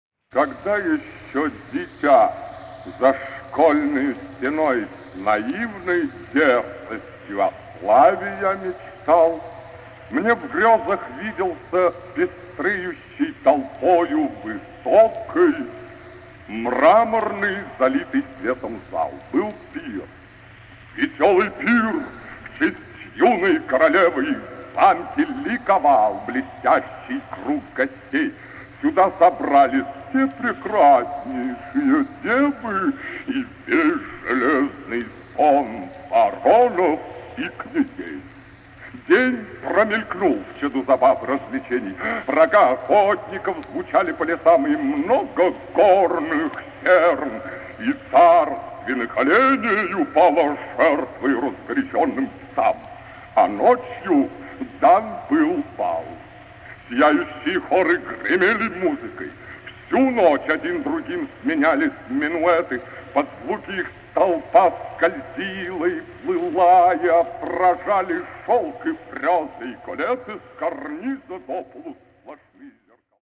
Приложение к письменному докладу: фонограммы-примеры
Как Ф. Шаляпин читал Надсона